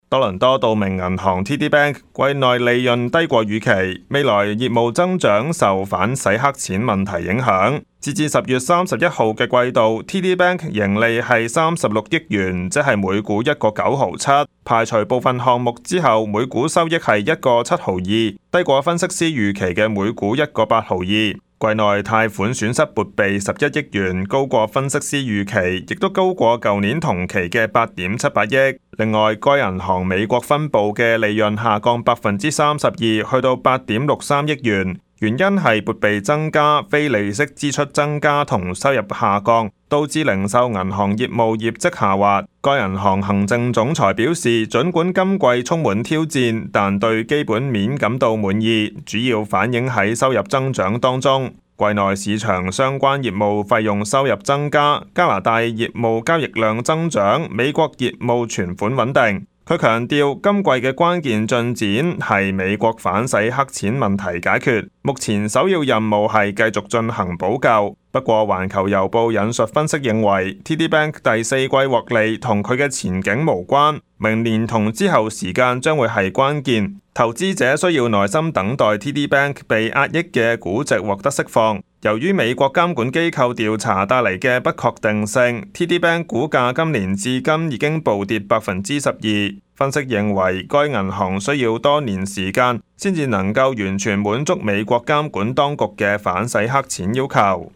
Canada/World News 全國/世界新聞